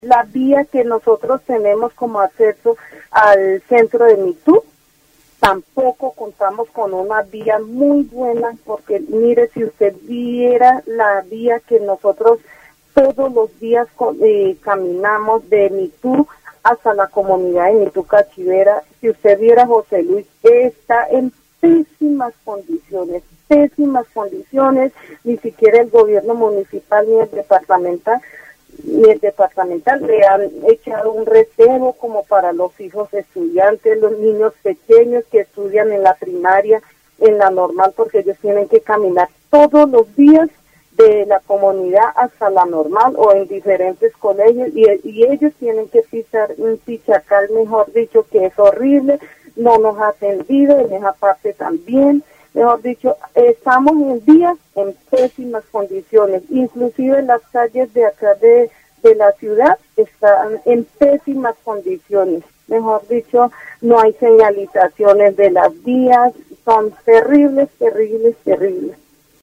El entrevistado describe las malas condiciones de las vías en su comunidad y solicita al gobierno que realice mejoras.
Vaupés (Región, Colombia) -- Grabaciones sonoras , Programas de radio , Condiciones de infraestructura , Gestión gubernamental -- Vaupés (Región, Colombia)